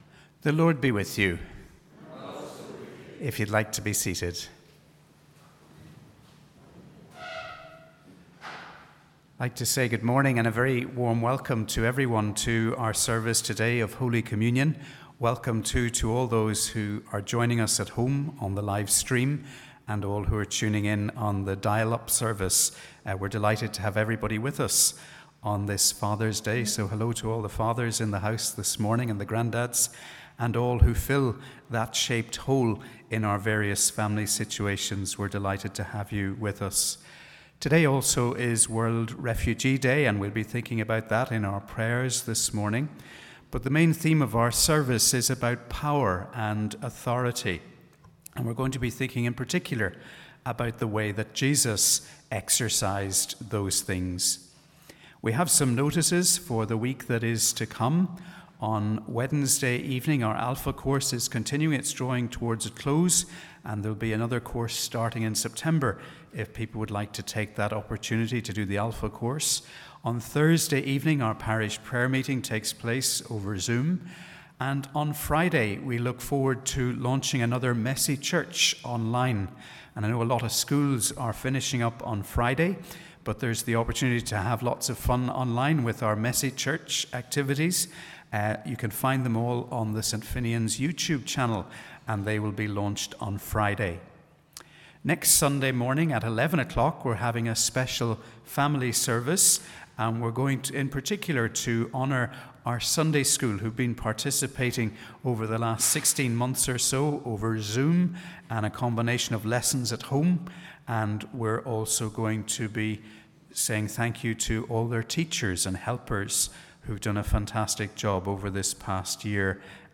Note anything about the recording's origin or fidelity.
Welcome to our service of Holy Communion on this 3rd Sunday after Trinity, which is also Father’s Day and World Refugee Day.